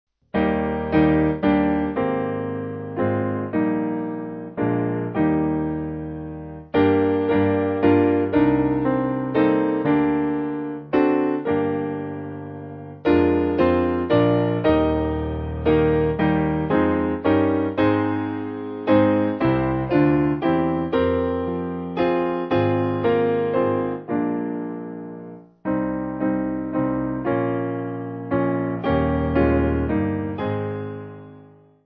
Simple Piano
4/Eb